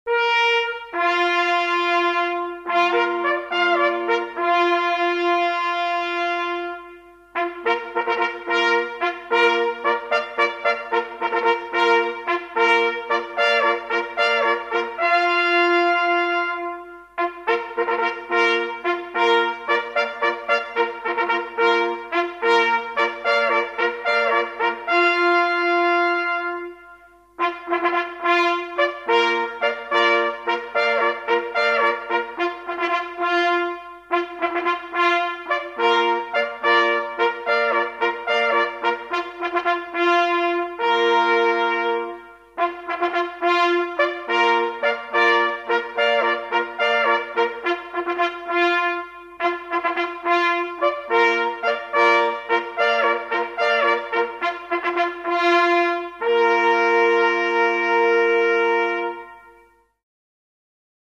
Jagdhornbläser
Wenn auch die Jagdhörner in der Zahl der zur Verfügung stehenden Töne begrenzt sind, so vermitteln sie doch mit ihren einfachen Melodienfolgen beim Zuhörer einen unvergleichlichen naturverbundenen Eindruck.